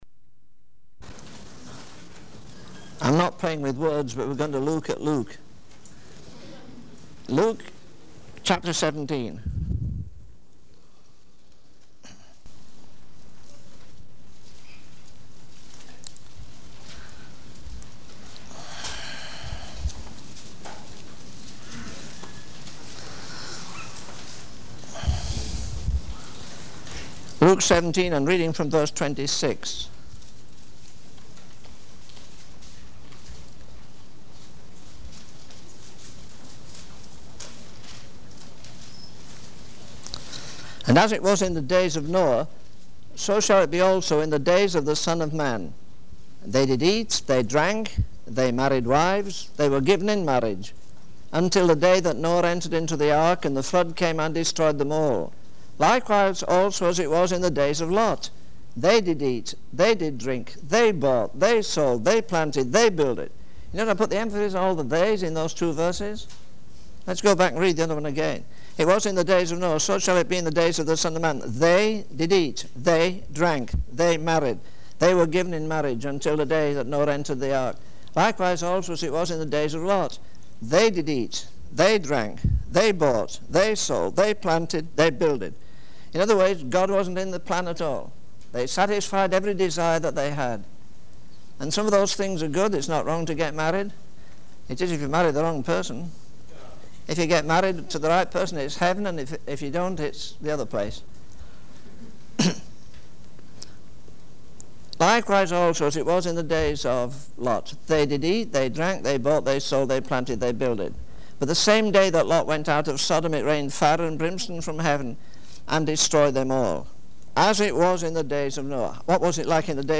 In this sermon, the preacher expresses concern about the state of the world and the lack of compassion shown towards those in need.